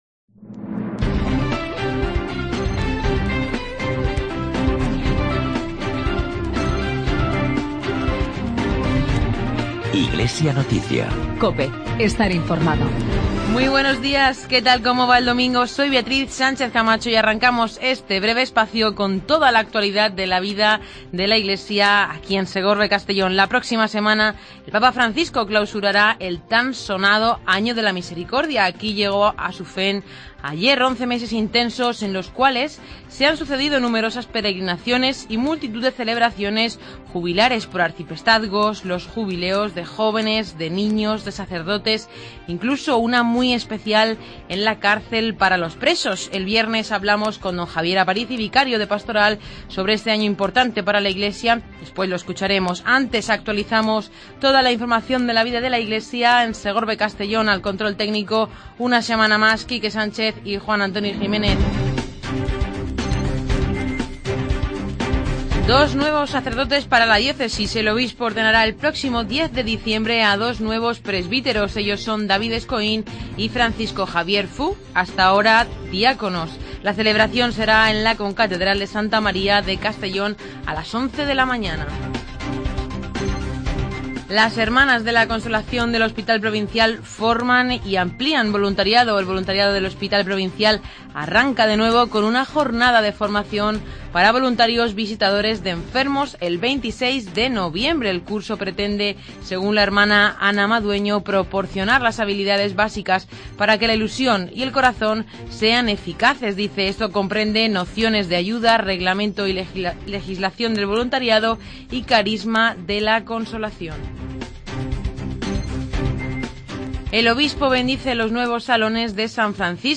Espacio informativo de la actualidad diocesana de Segorbe-Castellón